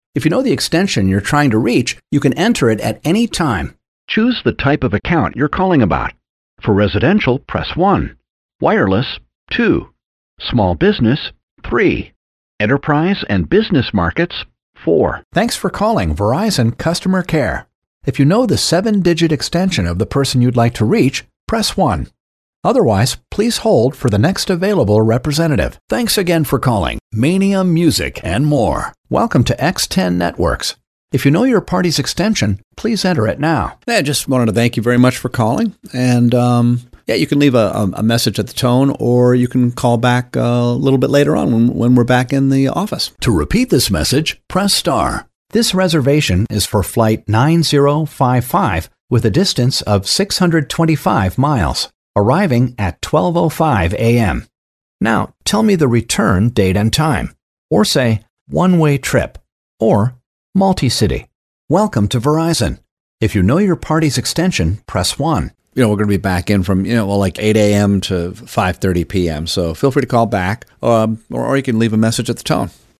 standard us
phone message